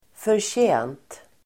förtjänt adjektiv, deserved Uttal: [förtj'ä:nt] Böjningar: förtjänt, förtjänta Definition: värd Exempel: vara förtjänt av en uppmuntran (deserve encouragement) Sammansättningar: välförtjänt (well-earned)